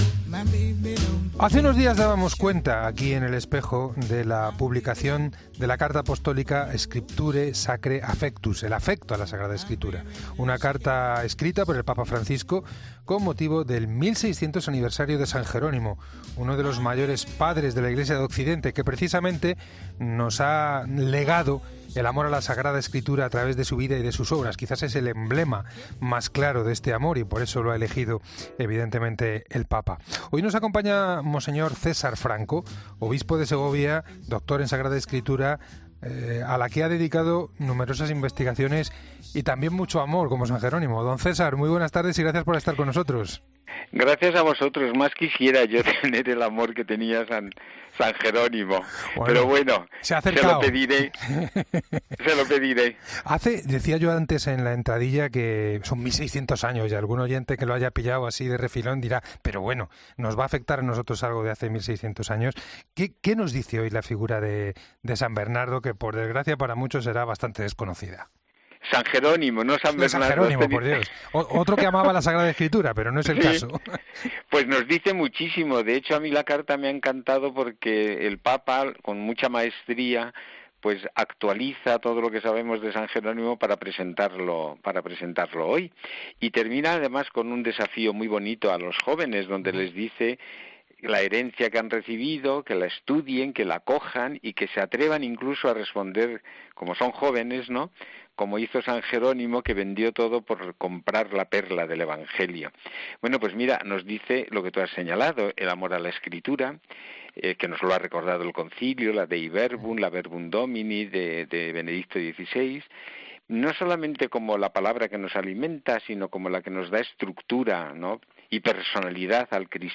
El obispo de Segovia, mons. César Franco, analiza la carta del Santo Padre